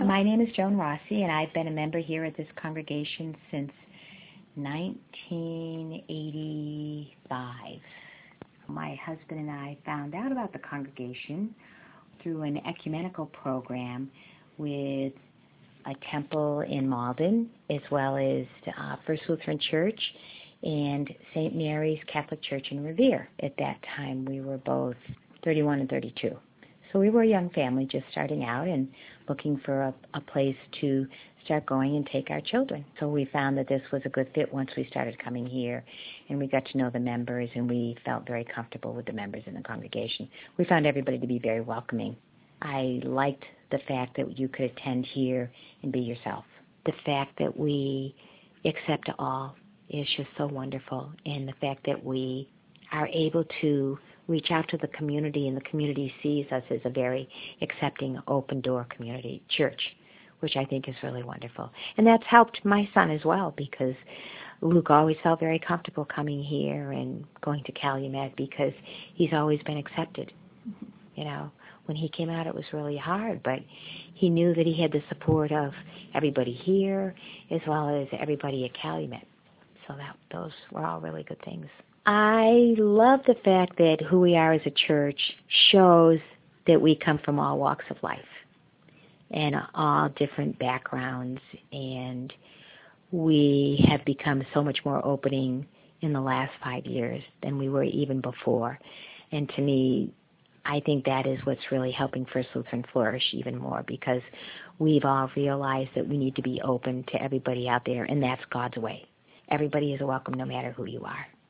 One way to find out is to share our holy curiosity about one another through interviews. Everyone in our community: members, visitors, friends of the congregation, folks who have been here once or every Sunday for the last fifty years, is invited to share your story of what has happened at First Lutheran Church, and how it has shaped you.